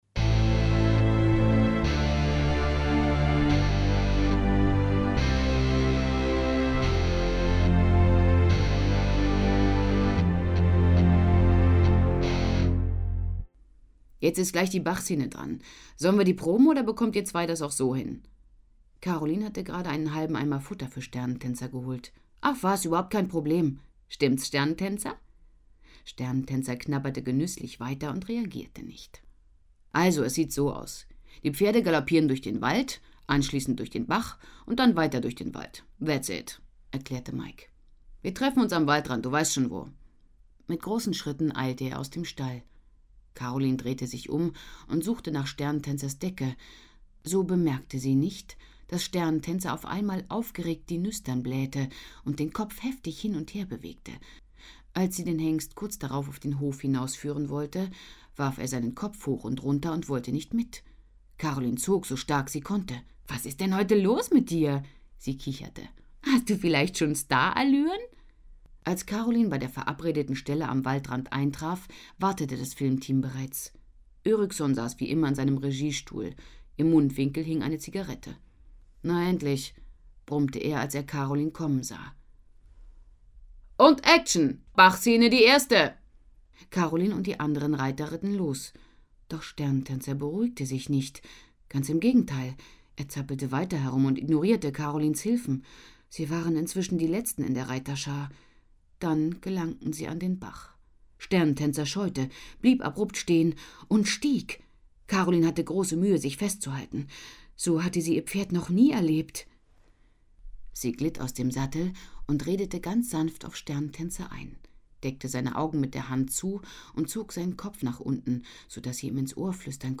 Hörbuch, 2 CDs, 159 Minuten